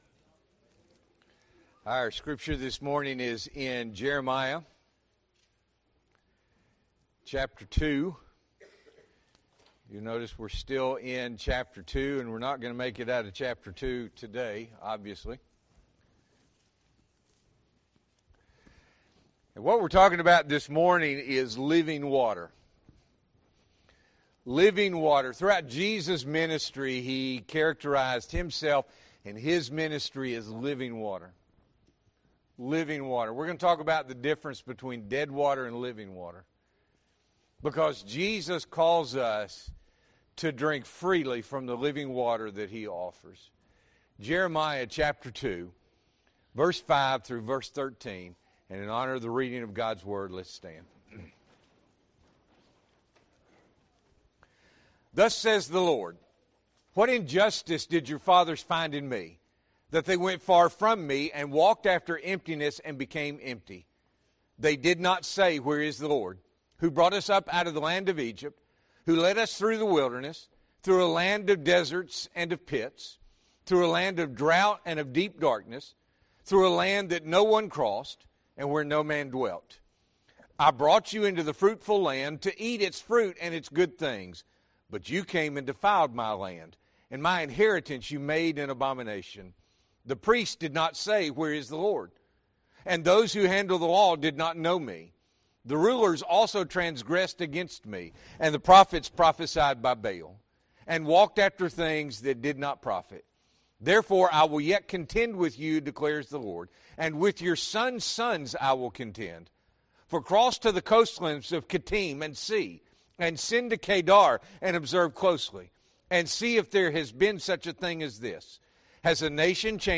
June 2, 2024 – Morning Worship